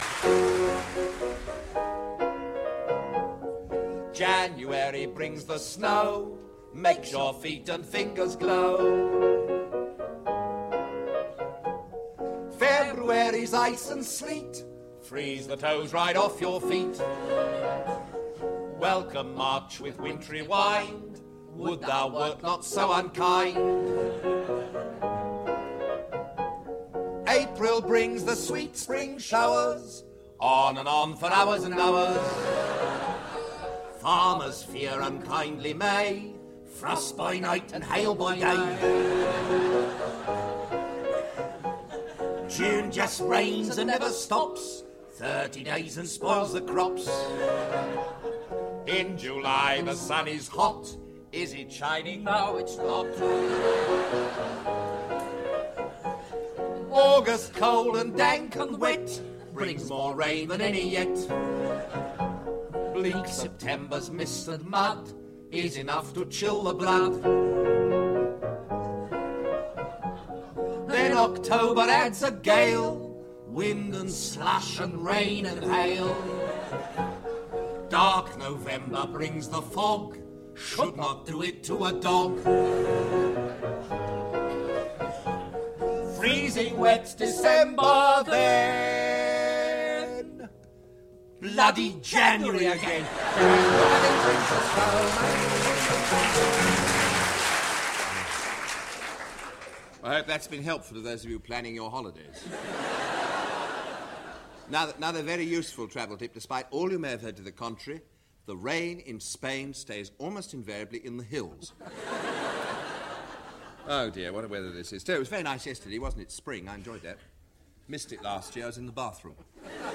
comedy duo